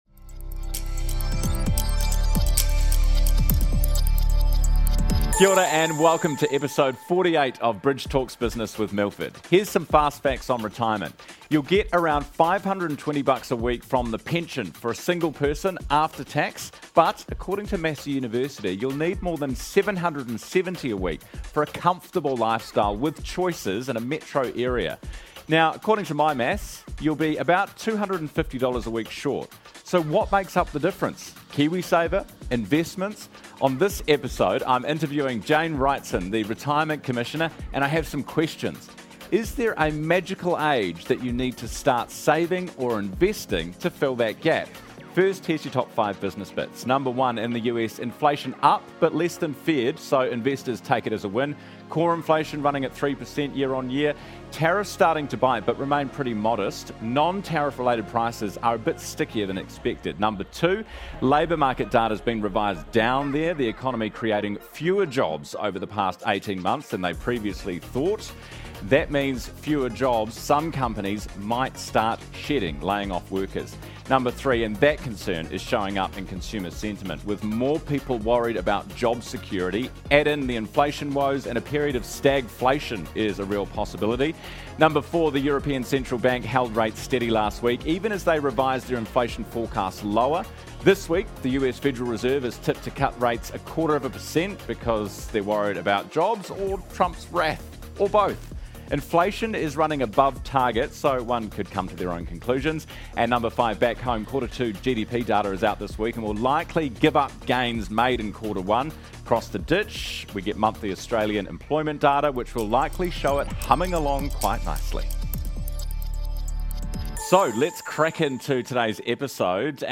Around 40% of New Zealanders solely rely on NZ Super in retirement, but is it really enough to have a comfortable lifestyle? Today on Bridge talks Business, Ryan Bridge meets with Retirement Commissioner Jane Wrightson to talk through her views on NZ Super, whether KiwiSaver should be compulsory, and possible changes to the retirement income policy.